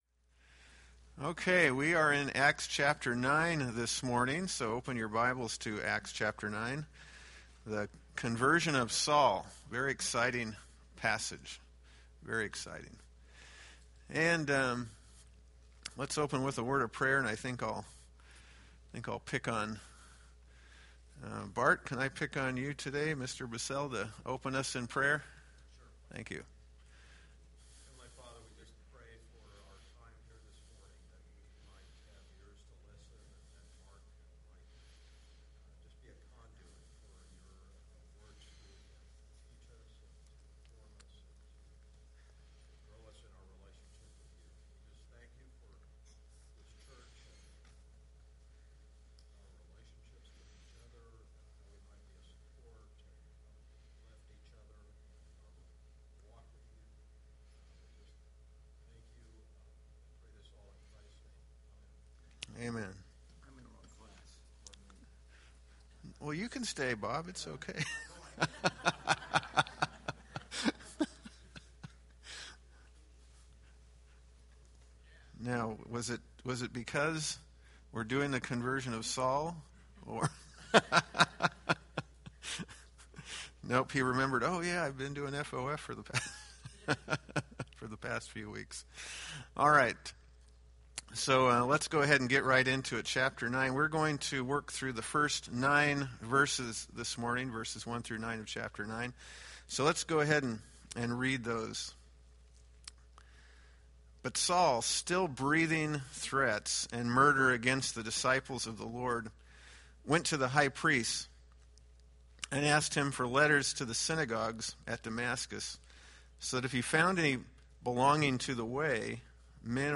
Date: Apr 6, 2014 Series: Acts Grouping: Sunday School (Adult) More: Download MP3